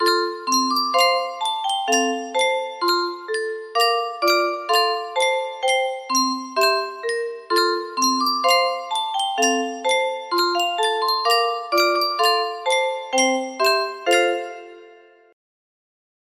Yunsheng Music Box - Up On the Housetop Y158 music box melody
Full range 60